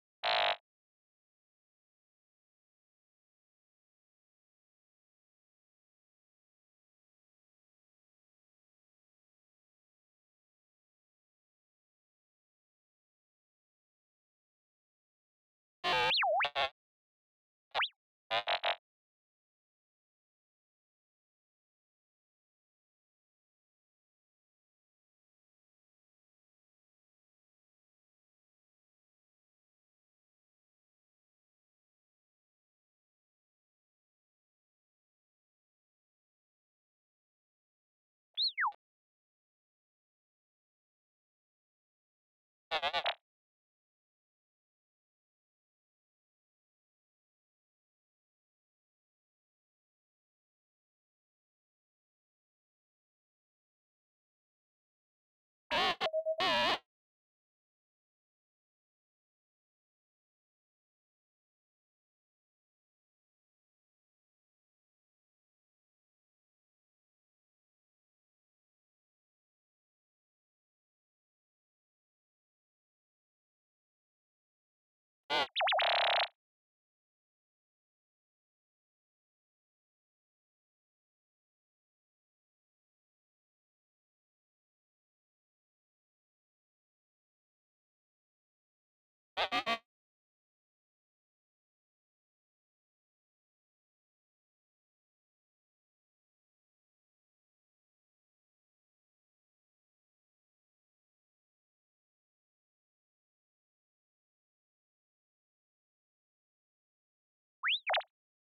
cicero droid sound.wav